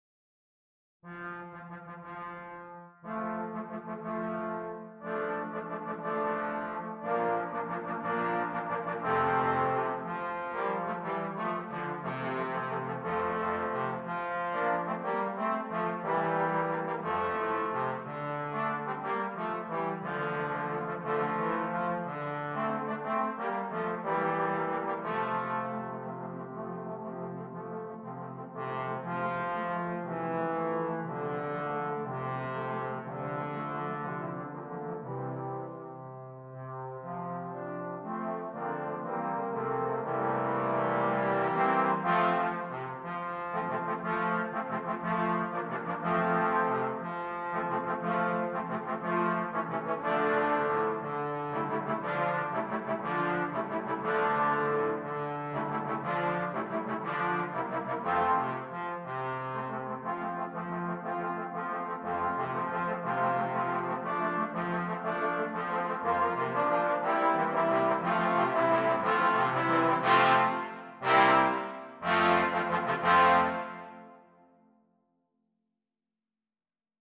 5 Trombones